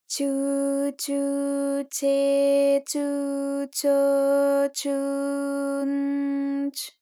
ALYS-DB-001-JPN - First Japanese UTAU vocal library of ALYS.
chu_chu_che_chu_cho_chu_n_ch.wav